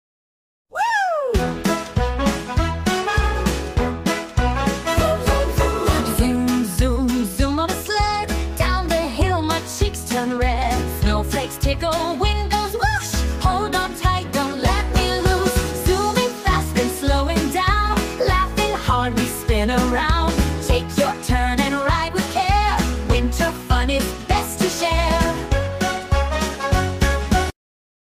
Winter Fun Rhyme
Nursery Rhyme